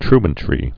(trən-trē)